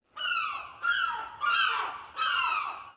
gull1.mp3